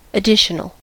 additional: Wikimedia Commons US English Pronunciations
En-us-additional.WAV